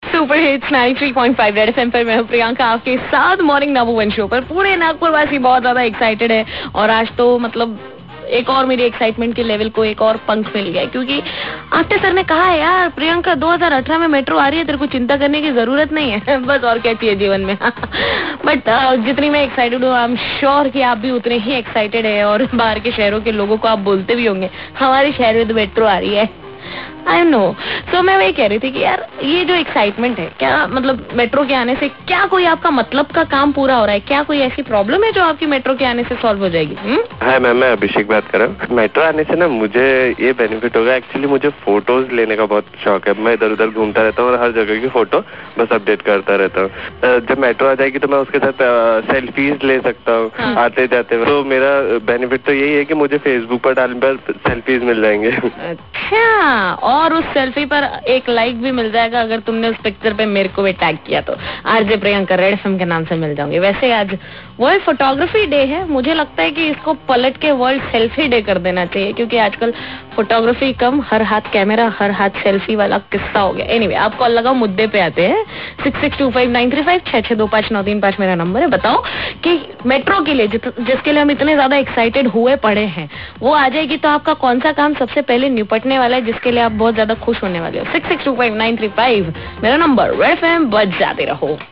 INTERACTION WITH CALLERS